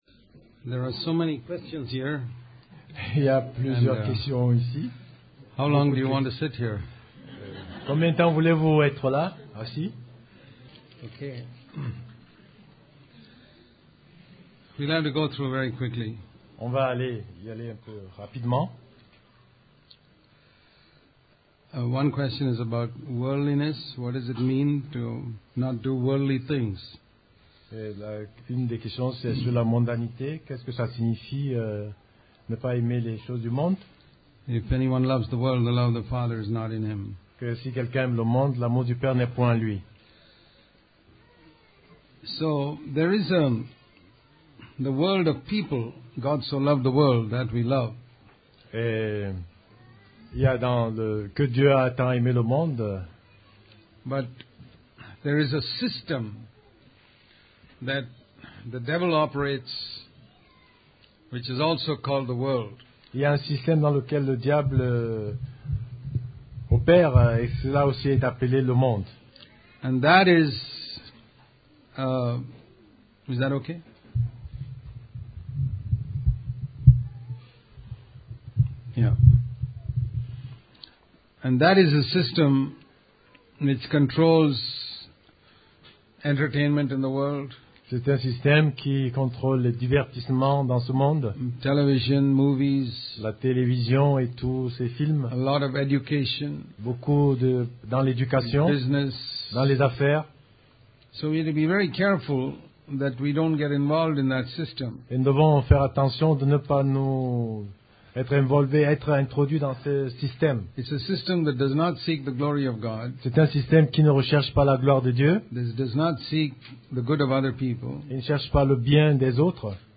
Question & Answer
8-q-and-a-session.mp3